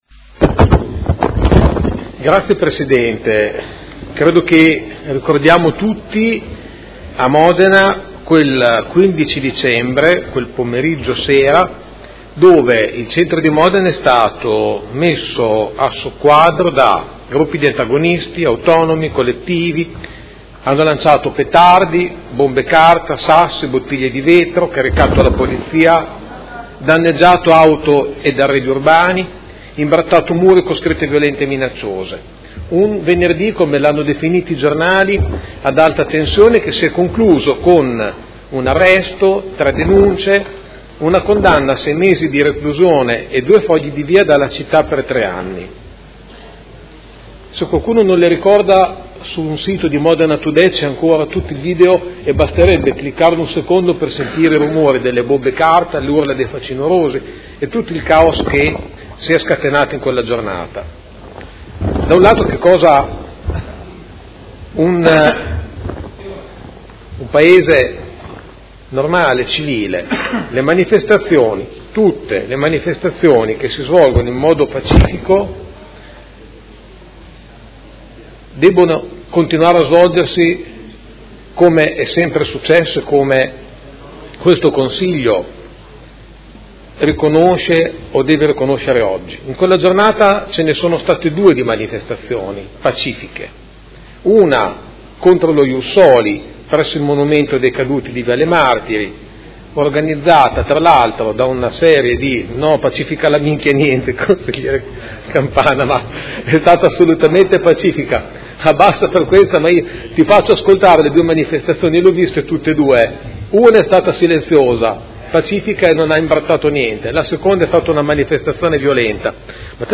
Seduta del 19/04/2018. Mozione presentata dal Consigliere Pellacani (Energie per l’Italia) avente per oggetto: Condanna delle manifestazioni violente che hanno invaso e messo a soqquadro il centro di Modena il 15 dicembre scorso ed impegno ad evitare in futuro il ripetersi di analoghe situazioni